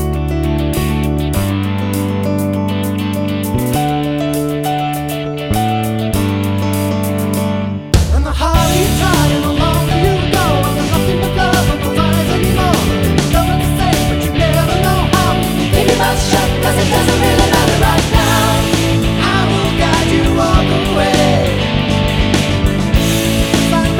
No Girl Rock 7:04 Buy £1.50